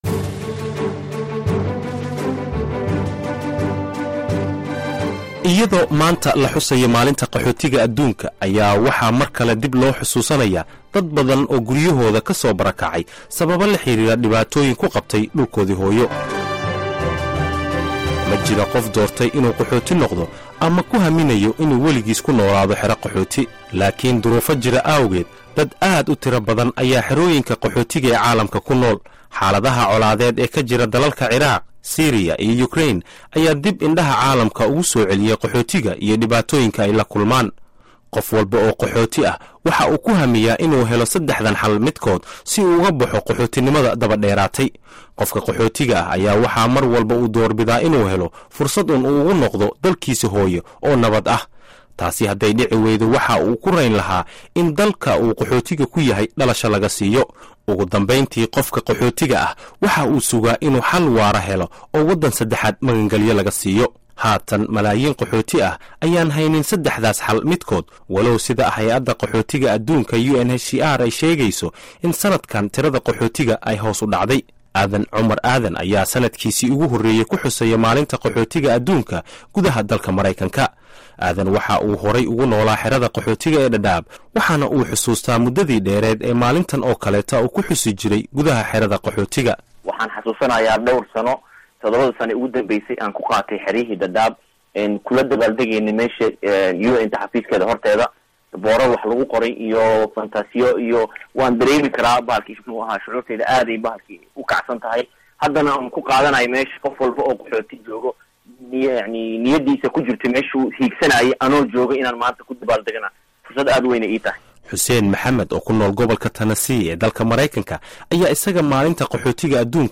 WARBIXIN: MAALINTA QAXOOTIGA